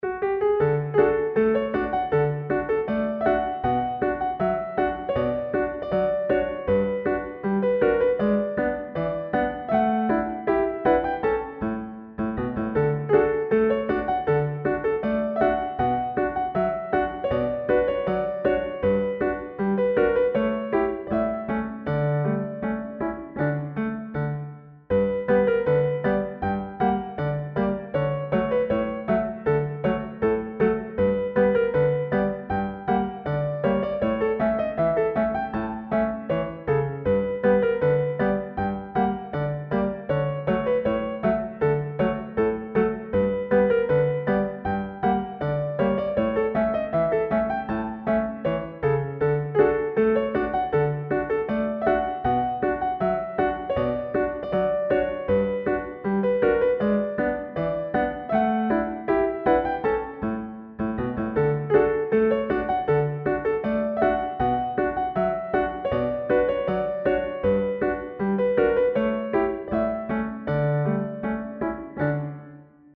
普通に明るいラグタイム風ほのぼのBGMです。